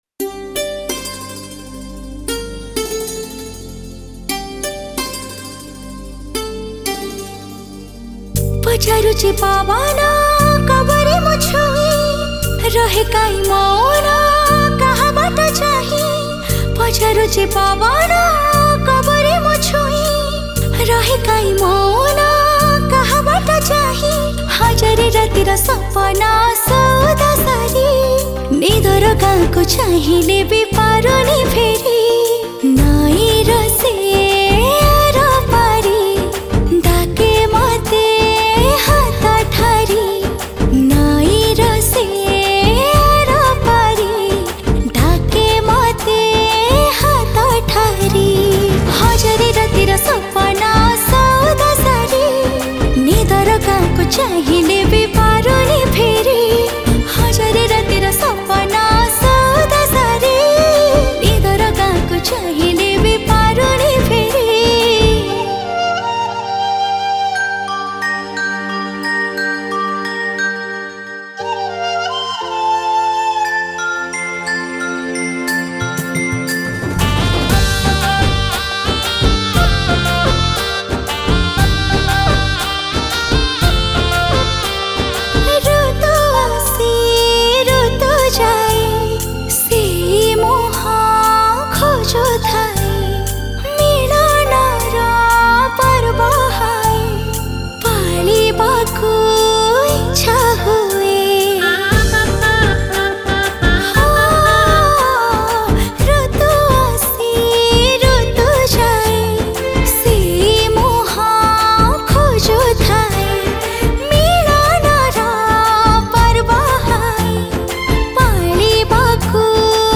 Romantic Song Music